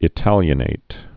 (ĭ-tălyə-nāt, -nĭt)